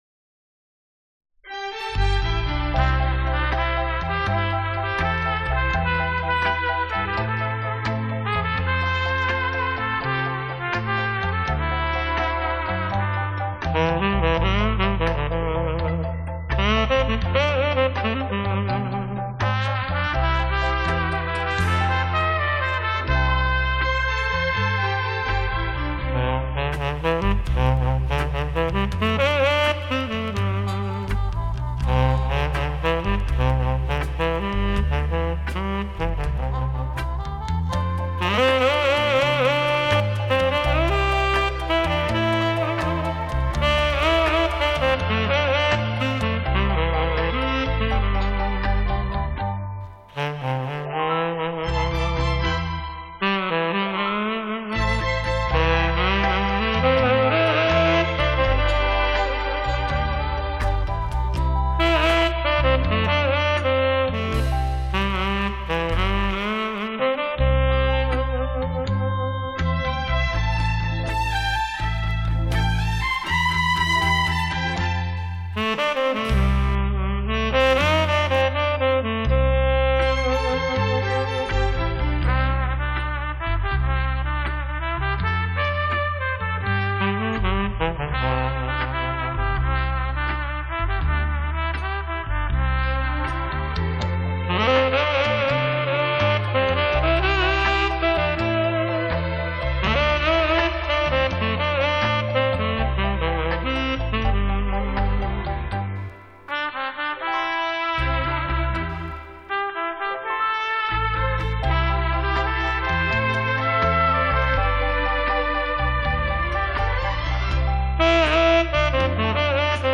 音质绝对一流。